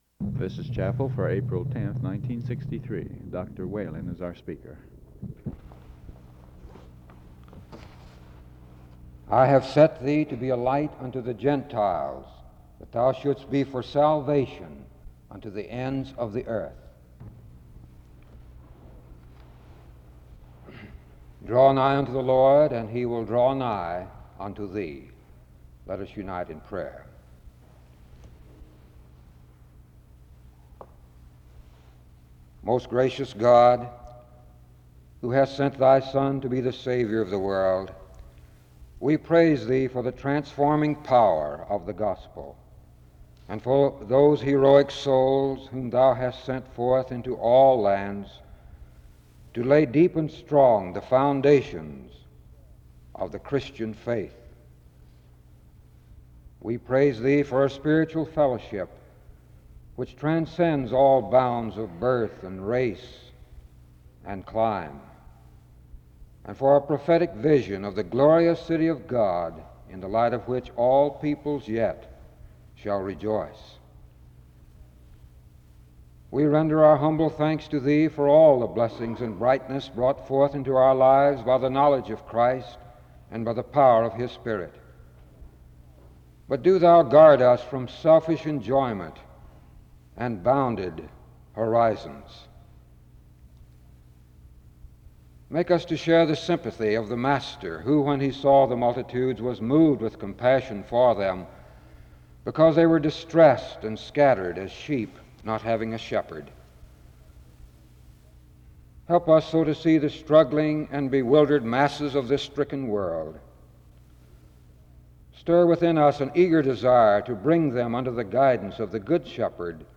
Wake Forest (N.C.)
SEBTS Chapel and Special Event Recordings